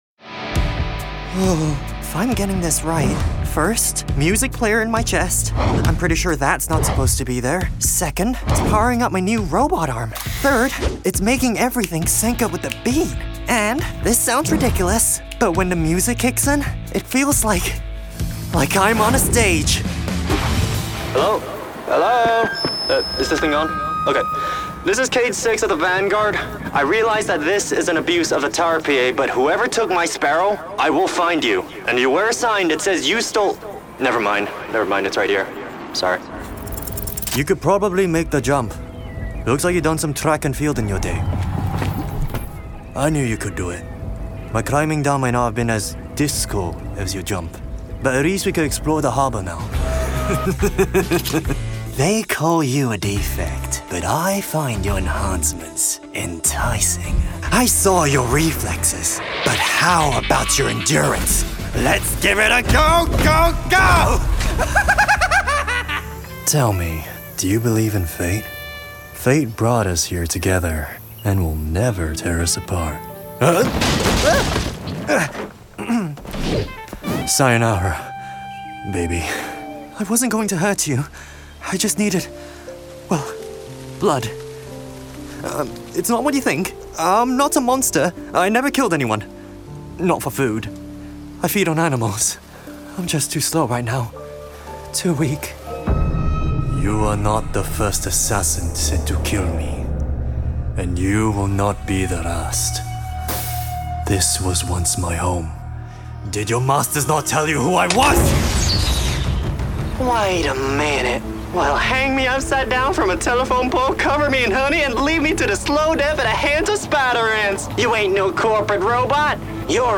Video Game